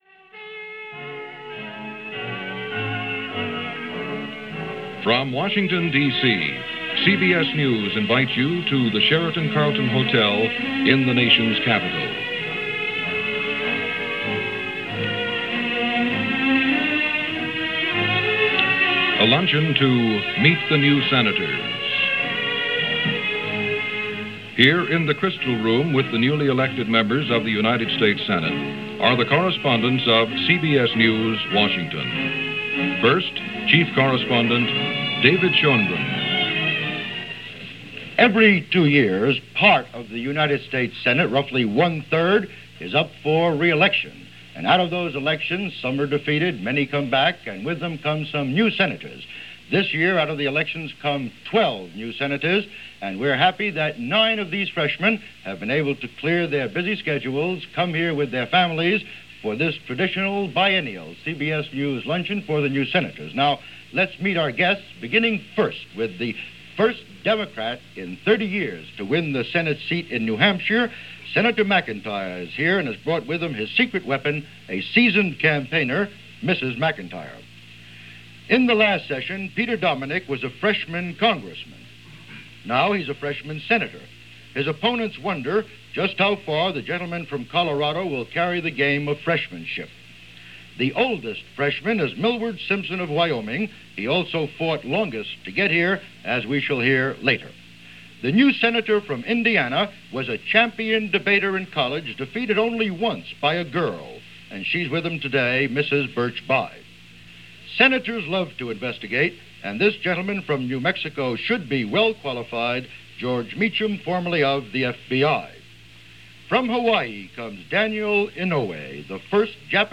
CBS News
At one point, the news anchor wonders which of this class of 12 new Senators would still be on Capitol Hill come the year 2000 – truths to tell, only one – Daniel Inouye of Hawaii would be the last one standing from this freshman group.